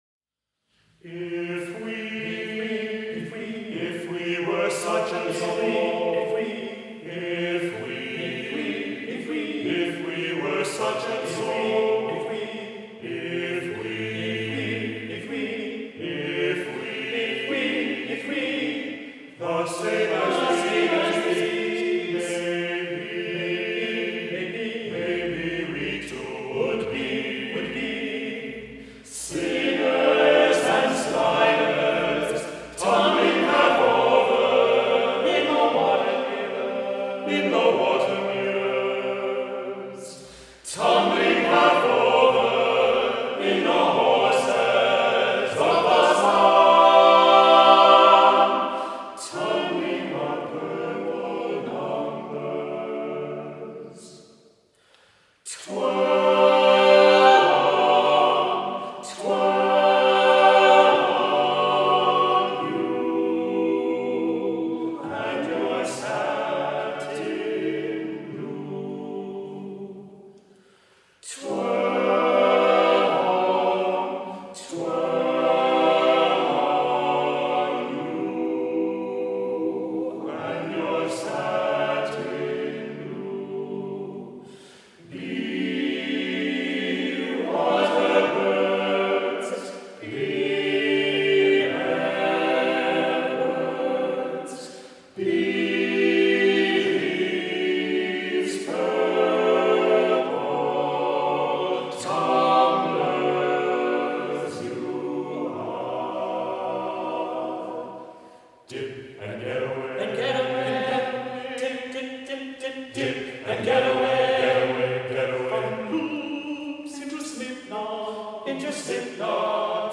for unaccompanied TTBB men's chorus